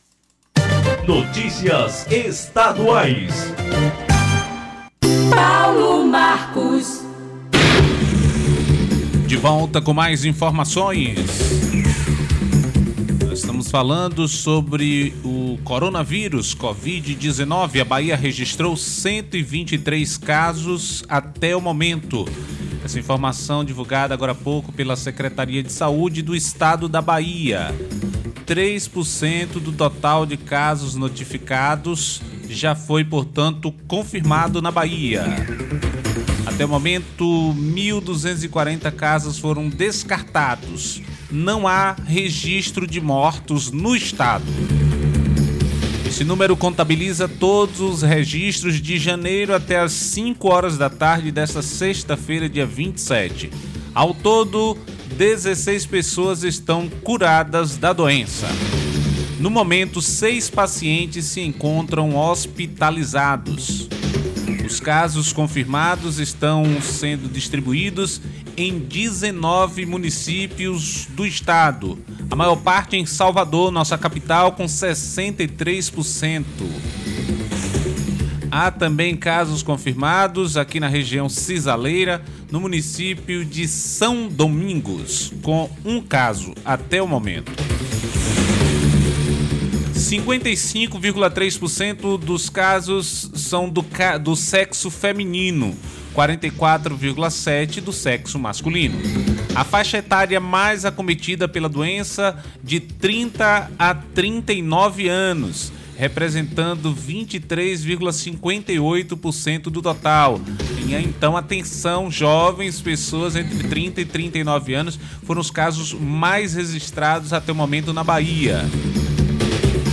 BOLETIM-CORONAVIRUS-BAHIA-.mp3